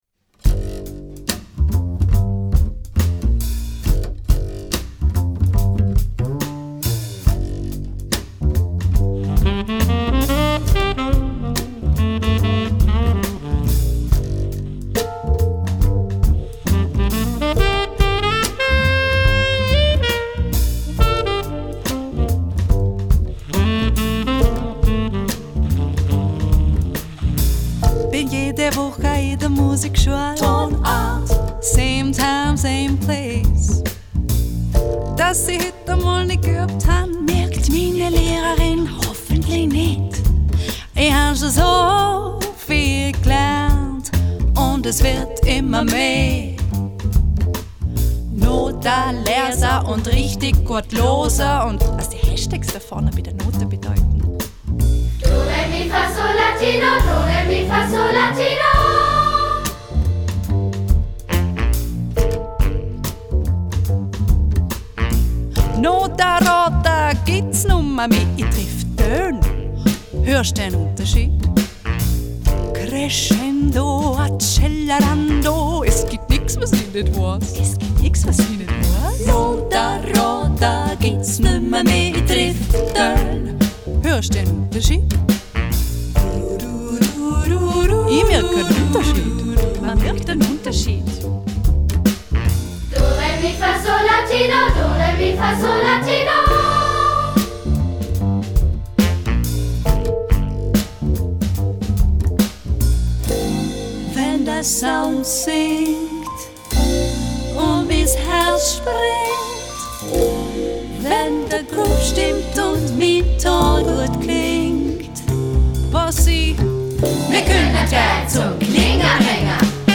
Der Song im coolen Hip-Hop-Shuffle-Groove bringt das Leben an der Musikschule wunderbar zum Ausdruck.
Schlagzeug
Bass
Saxophon
Piano
Backing Vocals